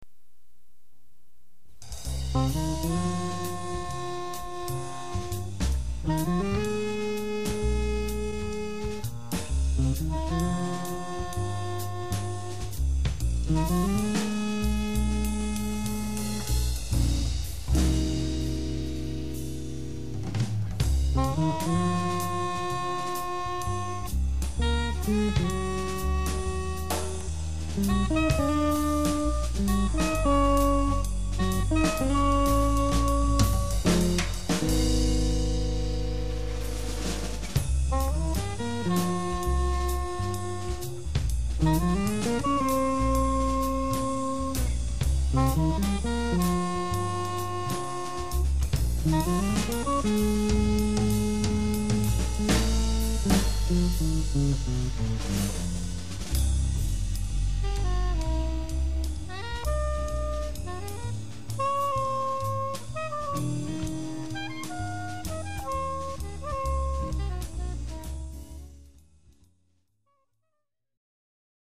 acoustic jazz quartet